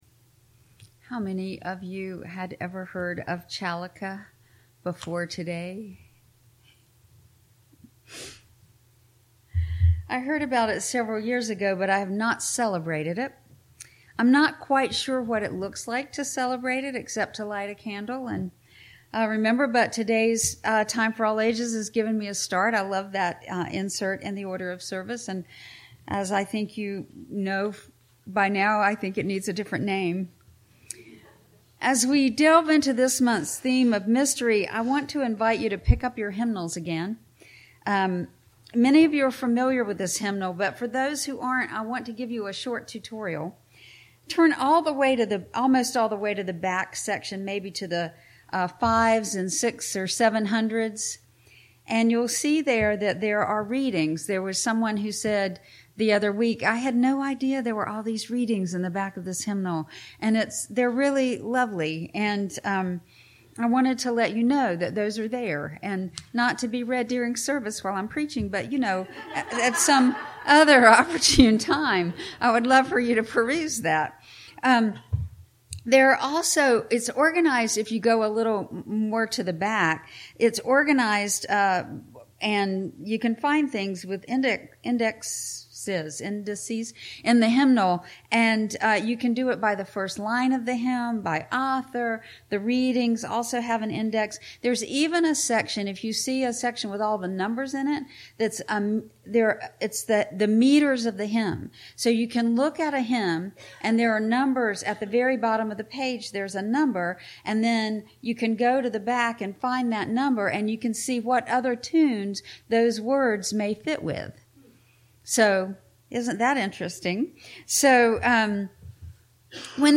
This sermon explores the evolving nature of Unitarian Universalism, characterized as a living tradition that prioritizes ethical actions over rigid dogma.